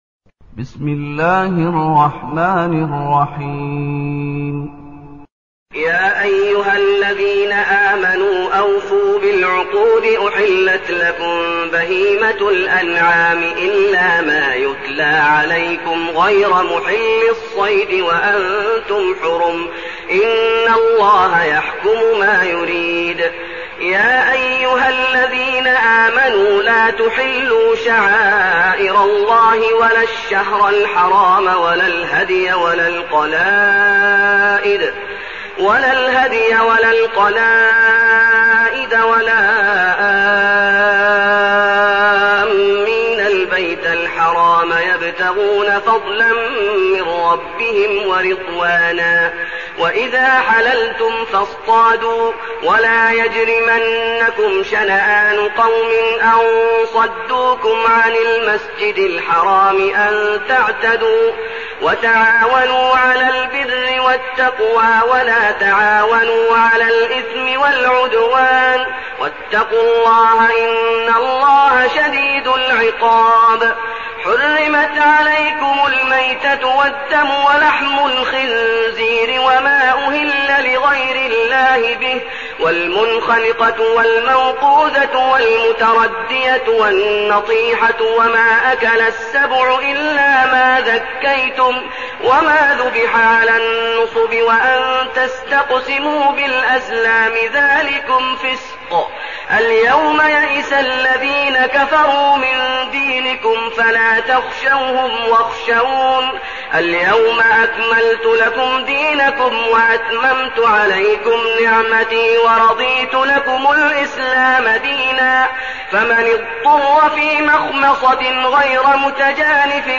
المكان: المسجد النبوي الشيخ: فضيلة الشيخ محمد أيوب فضيلة الشيخ محمد أيوب المائدة